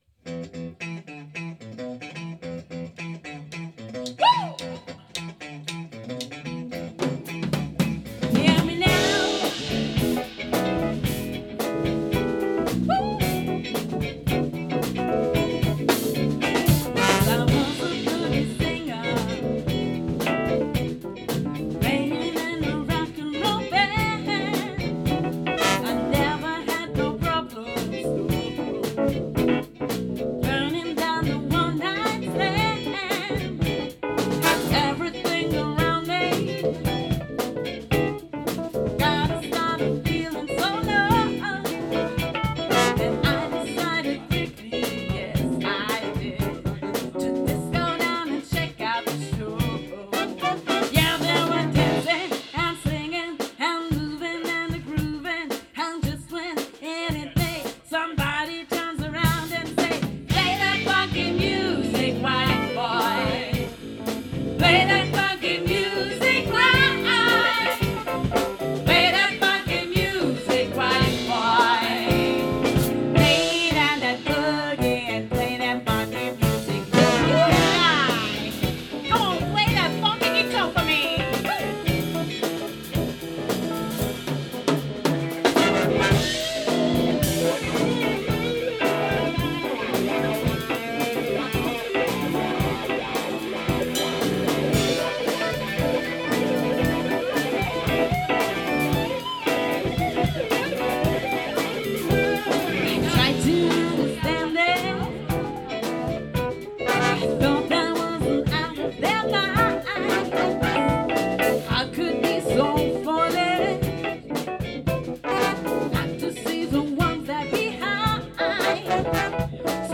· Genre (Stil): Soul
· Kanal-Modus: mono · Kommentar